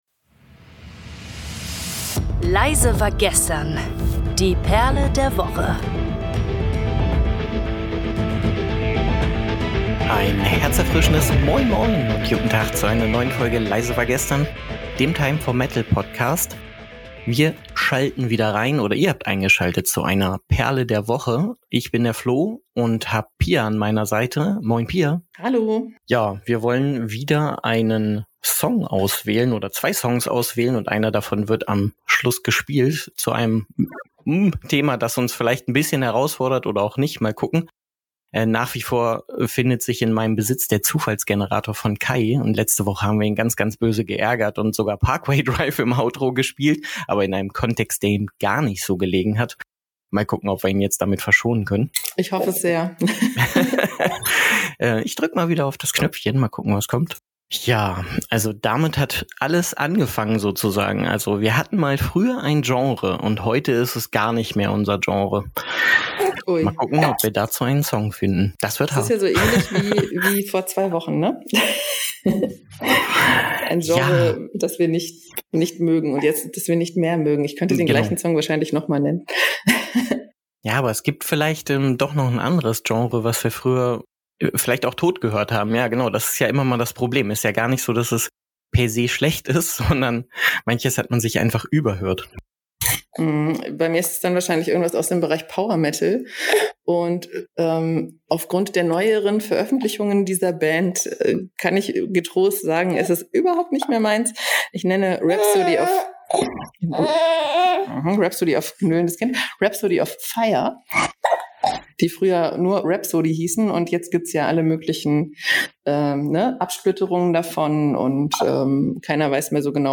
"Doris" wird als Outrosong für das Outro ausgewählt.